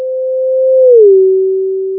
The source is initially placed at the far left of the line, and moves towards the far right. The following plots reflect a trajectory length of 100 m, a velocity of 50 m/s, and an observer located at 50 m from the left of the start trajectory and 10 m away from the closest point on the line. F0 is 440 Hz.